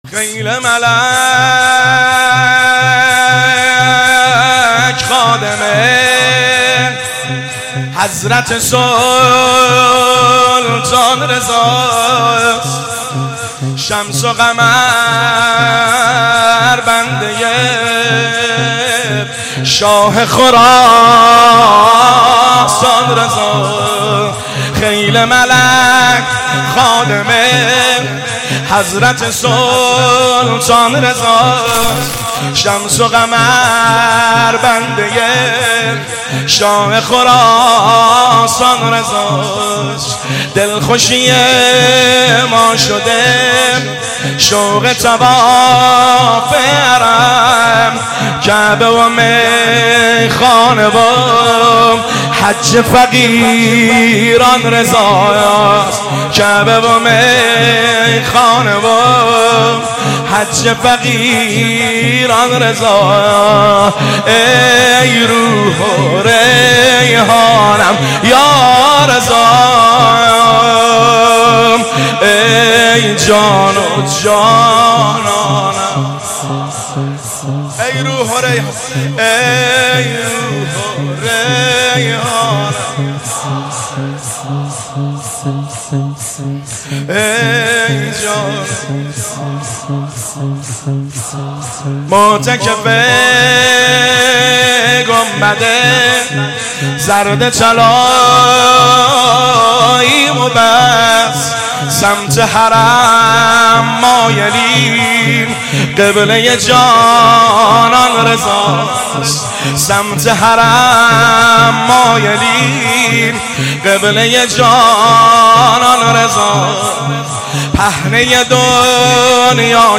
شور – شام 29 صفرالمظفر 1398